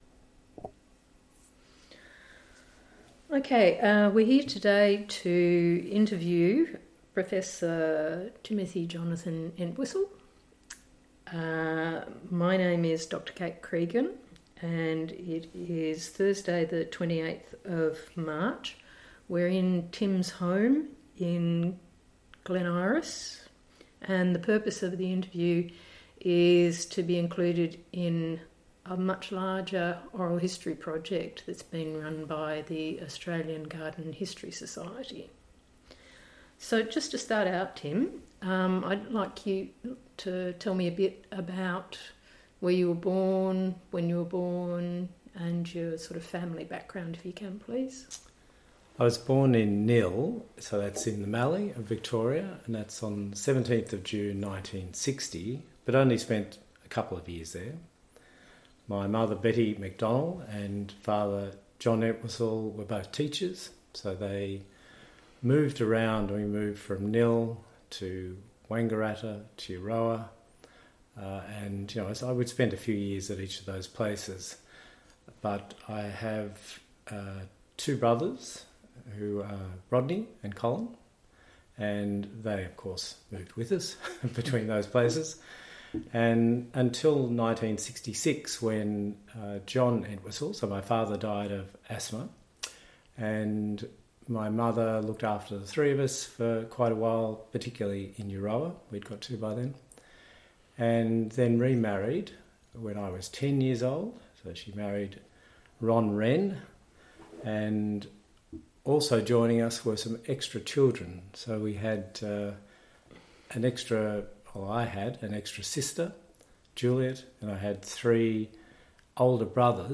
Interview Recording